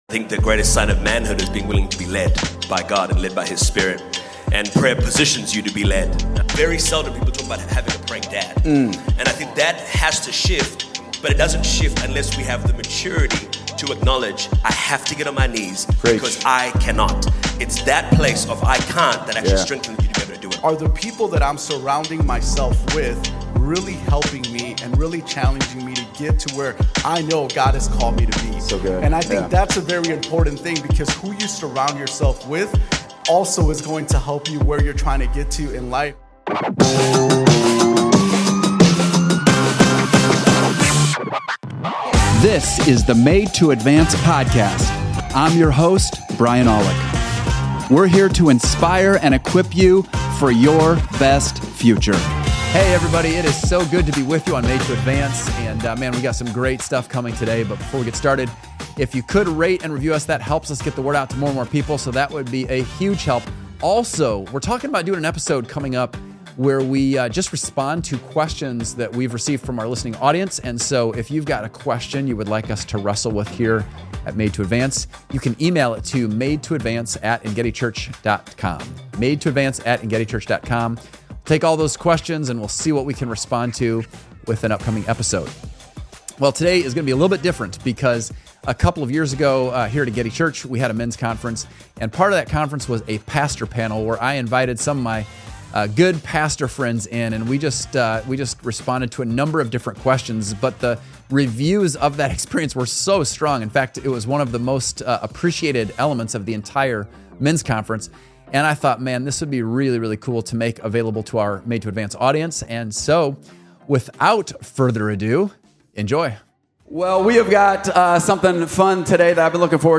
A Pastor Panel Discusses Biblical Manhood